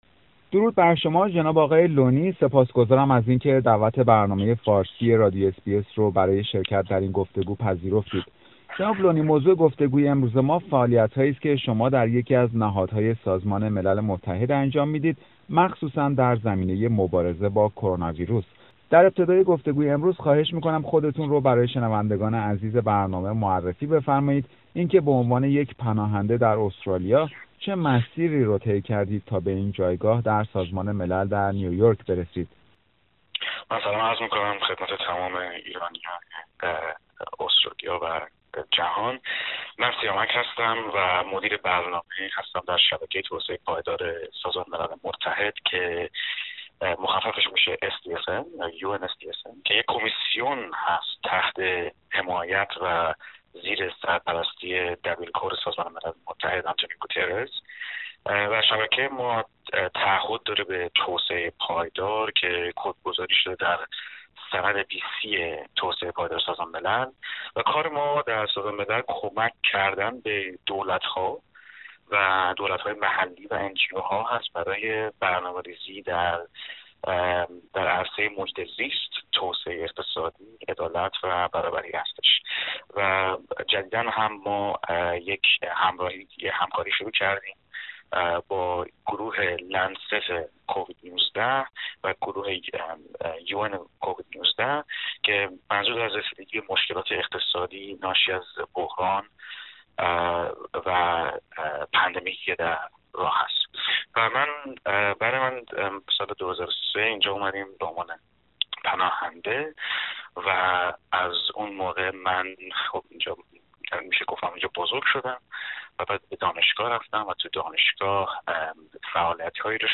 گفتگو با پناهنده سابق که اکنون مدیر برنامه های یکی از نهادهای سازمان ملل است